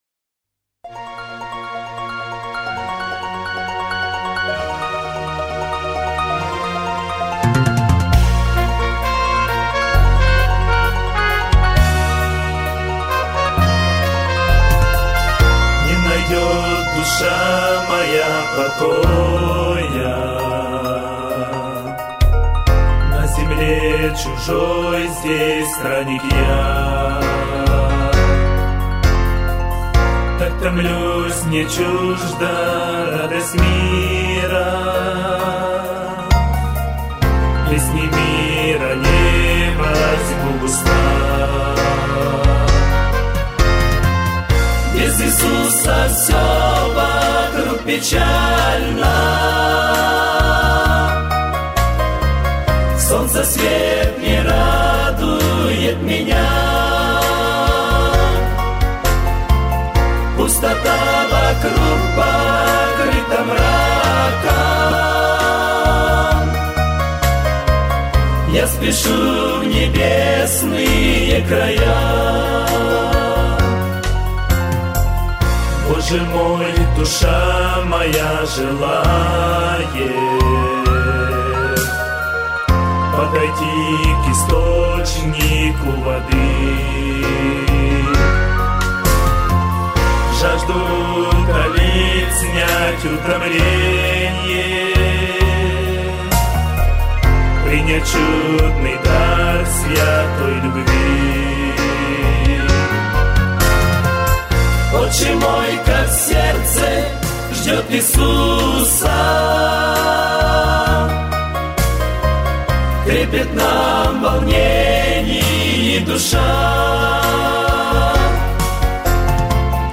1339 просмотров 1362 прослушивания 300 скачиваний BPM: 75